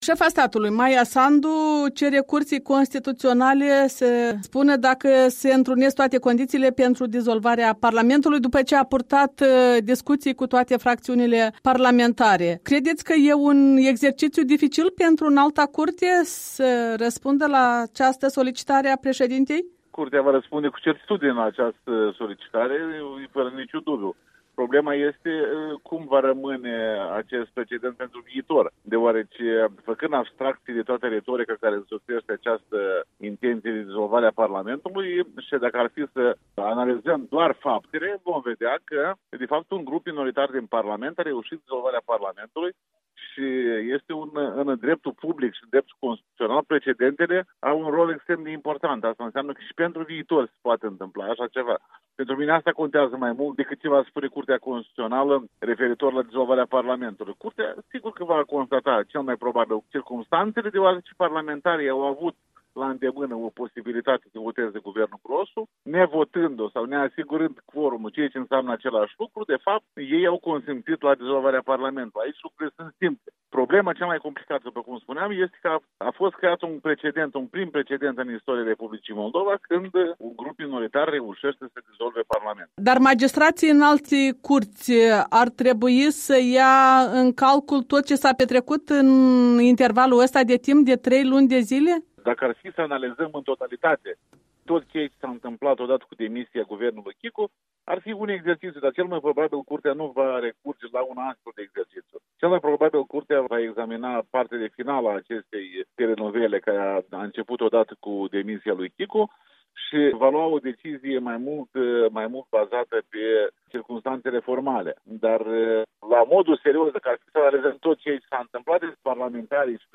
Interviu cu ex-președintele Curții Constituționale, fost ministru al justiției.
Interviu cu ex-președintele Curții Constituționale, Alexandru Tănase